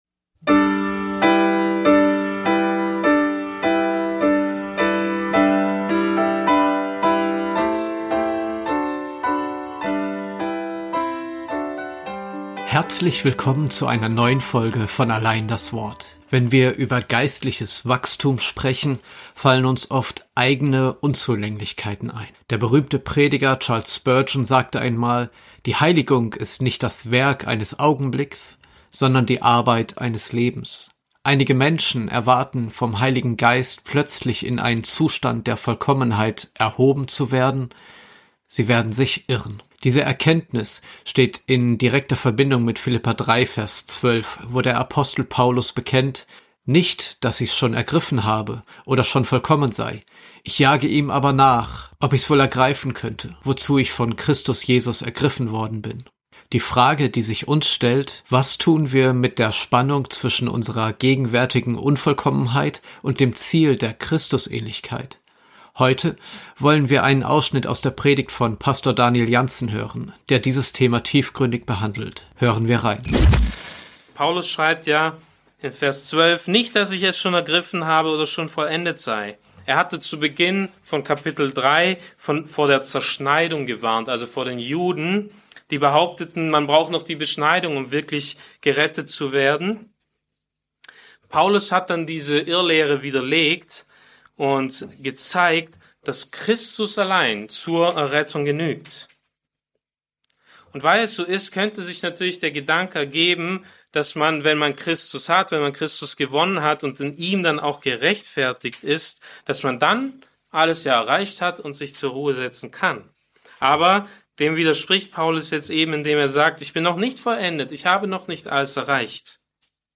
Eine ermutigende Botschaft für alle, die im Spannungsfeld